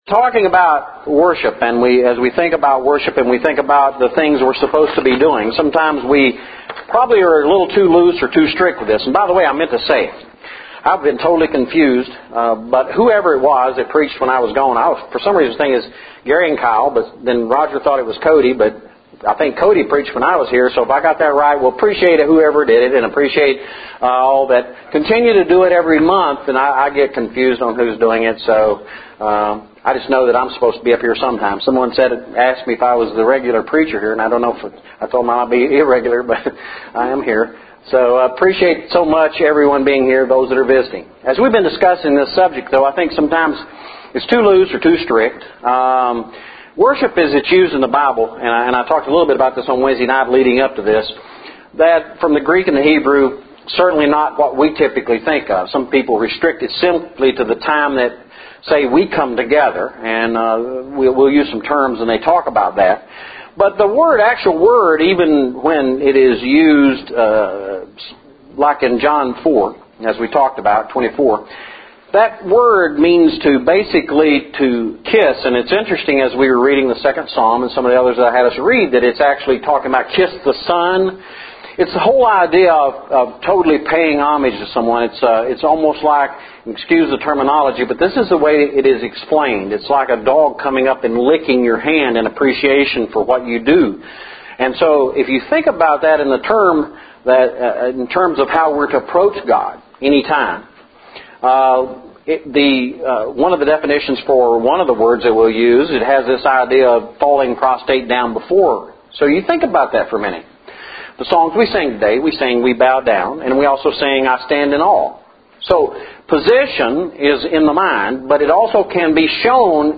Today was our 5th Sunday worship service where we have a mix of scripture readings, songs, prayer, and a short lesson for our first assembly, then a full lesson for our second assembly – both on a common theme.